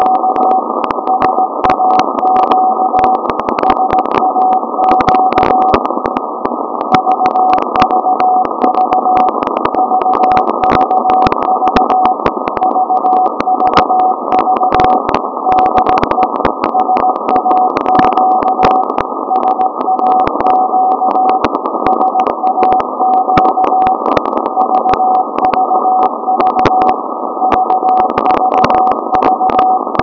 SAQ Transmission zum Alexanderson Tag am 30.06.2024
RST 578, very good Signal today! (